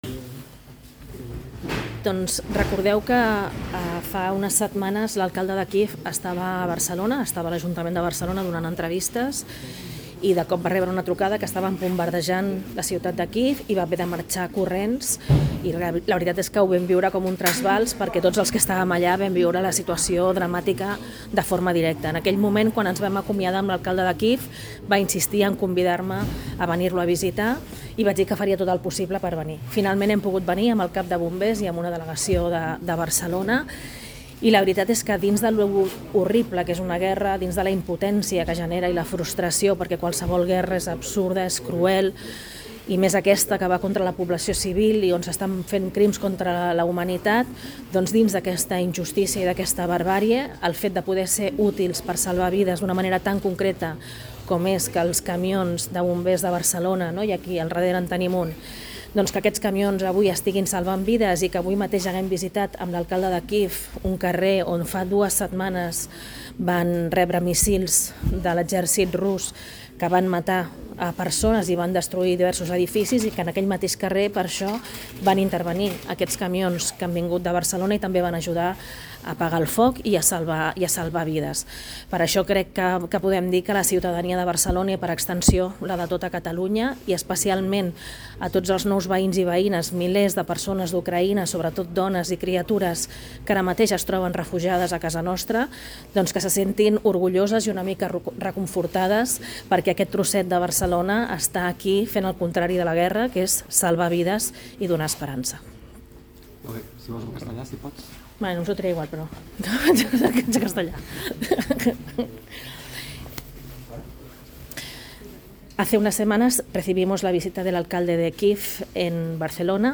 La nota incorpora fotos i àudios de la visita a les instal·lacions de Bombers
DECLARACIONS
Ada Colau, alcaldessa de Barcelona (CAT i CAST)